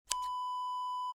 Scissors snip sound effect .wav #2
Description: The sound of a pair of scissors snipping
Properties: 48.000 kHz 16-bit Stereo
A beep sound is embedded in the audio preview file but it is not present in the high resolution downloadable wav file.
Keywords: scissors, snip, snipping, cut, cutting, hair, click, clicking
scissors-snip-preview-2.mp3